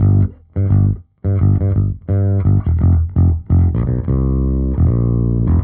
Index of /musicradar/dusty-funk-samples/Bass/85bpm
DF_JaBass_85-G.wav